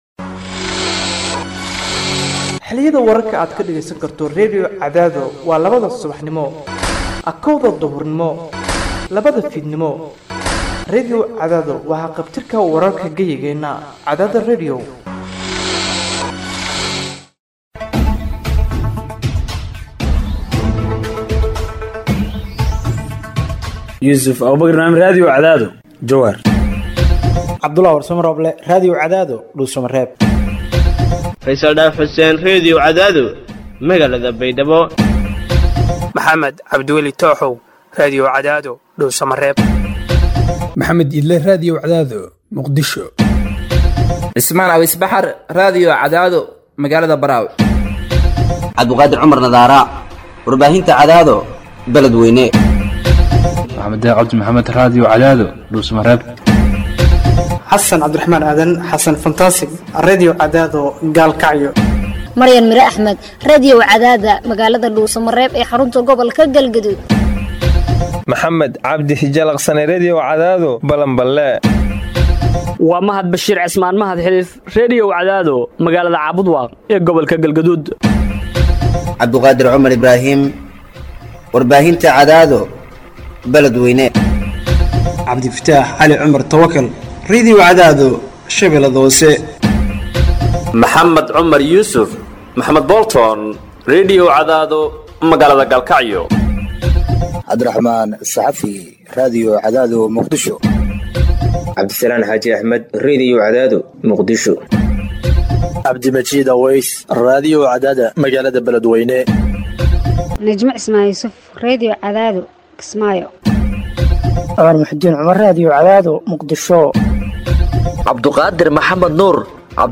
Hoos Ka Dhageyso Warka Duhur Ee Radio Cadaado